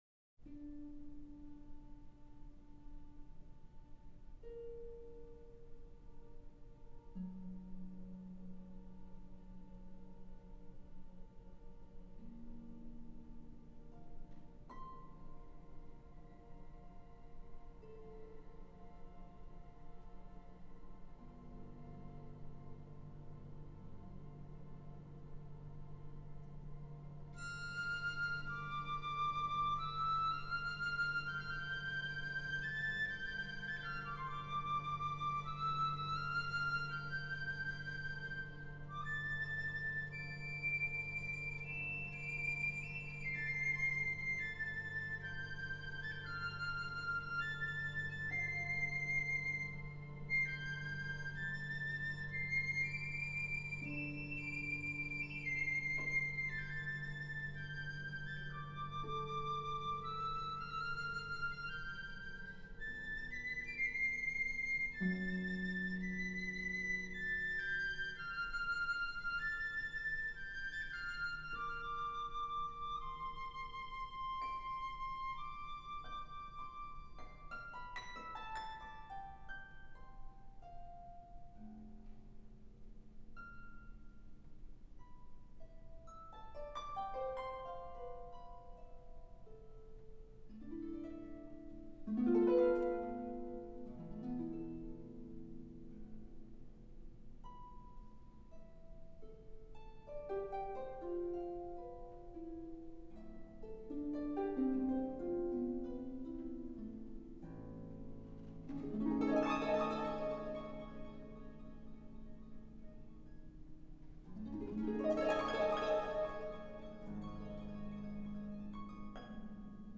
Recorded in 1998 at the Centennial Concert Hall in Winnipeg